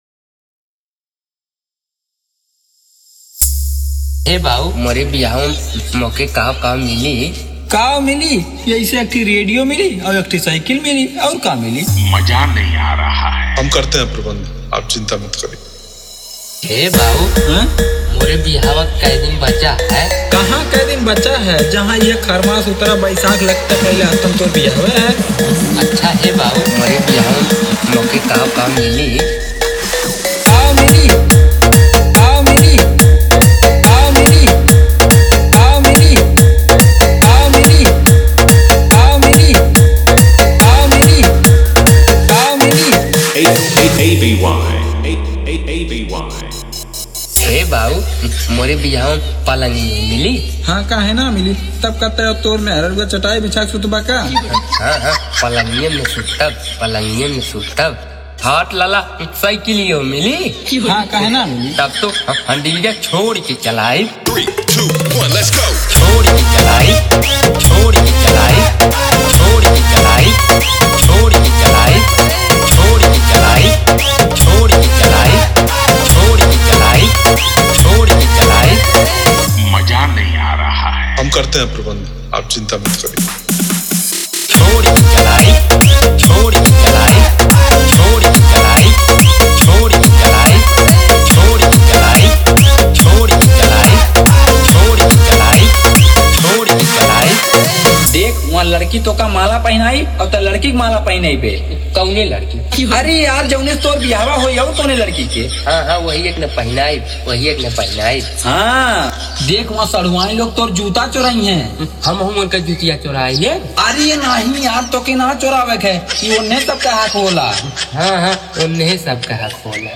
DJ Dialogue Intro Script – Comedy Dance Vibe
UP70 × Comedy × Naagin Beat Fusion – Sabki Band Baja Do!